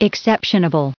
added pronounciation and merriam webster audio
1363_exceptionable.ogg